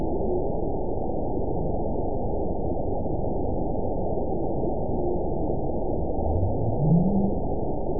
event 922359 date 12/30/24 time 02:32:34 GMT (5 months, 3 weeks ago) score 9.35 location TSS-AB02 detected by nrw target species NRW annotations +NRW Spectrogram: Frequency (kHz) vs. Time (s) audio not available .wav